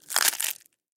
Звуки вафель
Звуки вафлей: хруст раздавленной ногой упавшей вафли